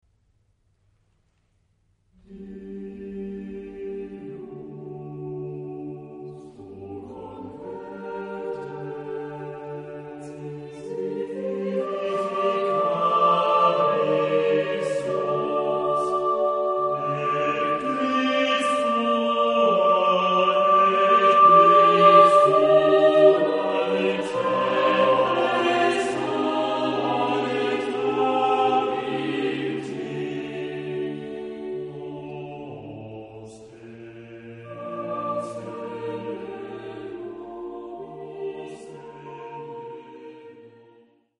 Género/Estilo/Forma: Sagrado ; Romántico ; Motete
Carácter de la pieza : adagio
Tipo de formación coral: SATB  (4 voces Coro mixto )
Tonalidad : sol menor